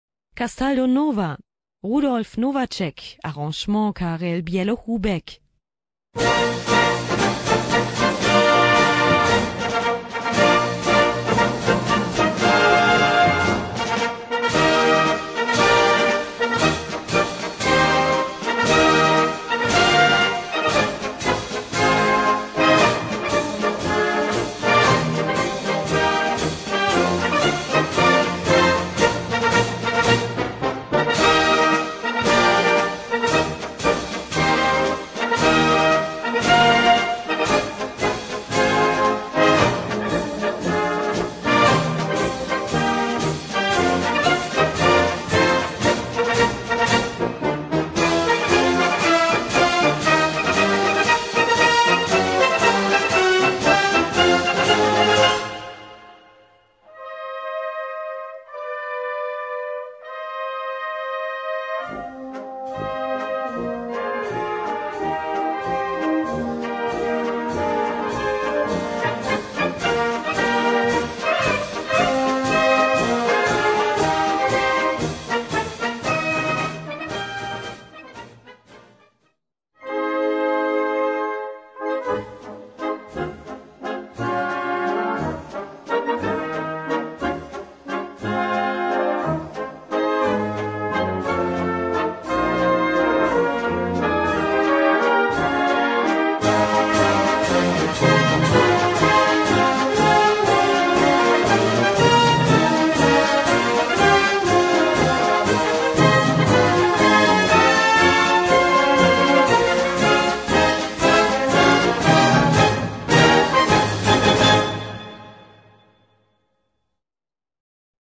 Gattung: Marsch-Walzer
Besetzung: Blasorchester